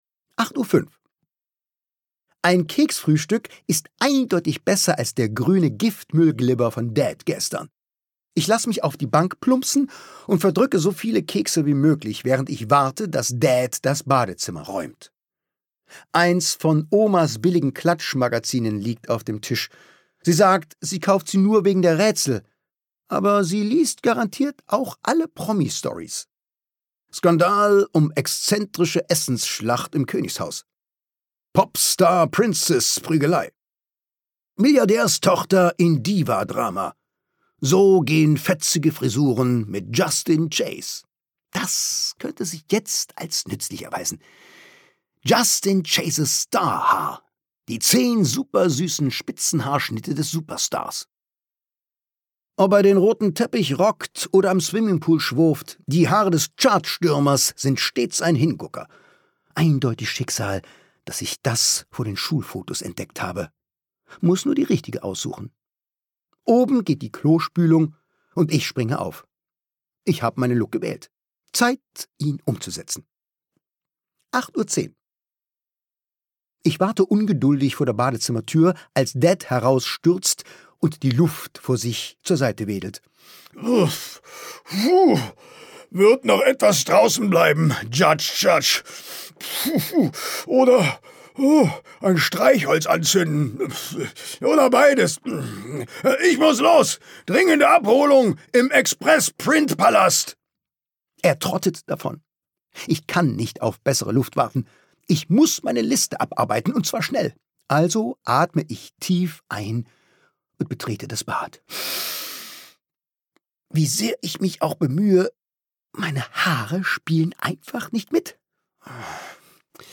Schule, Peinlichkeiten und ganz viel Humor – Cooles Hörbuch für Kinder ab 10 Jahre
Gekürzt Autorisierte, d.h. von Autor:innen und / oder Verlagen freigegebene, bearbeitete Fassung.